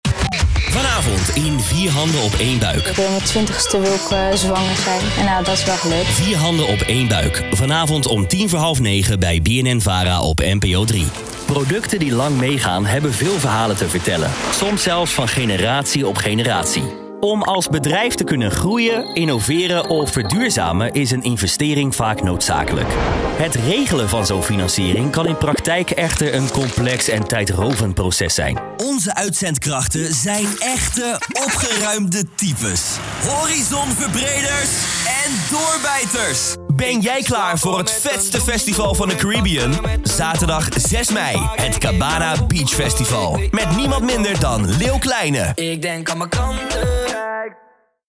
Stemdemo
De audio wordt schoon aangeleverd, zonder ruis, achtergrondgeluiden, klikjes en smakjes.